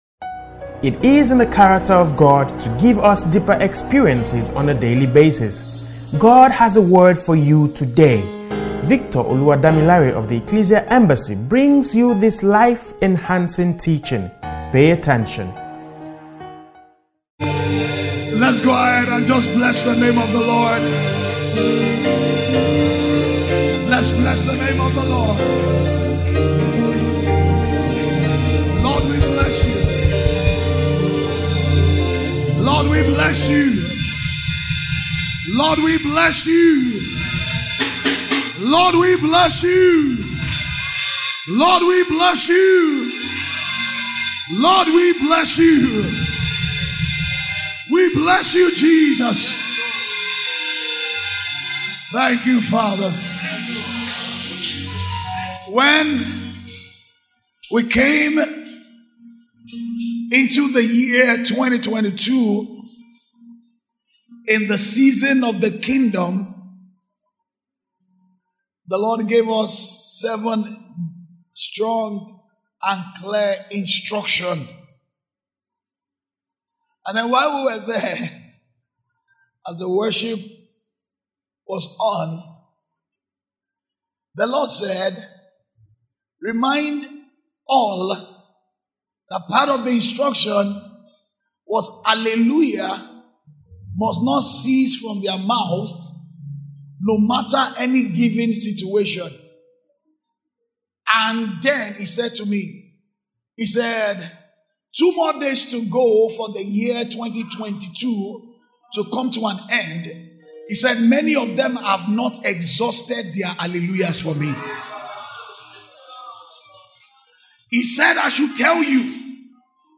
Sermons Archive - The Ecclesia Embassy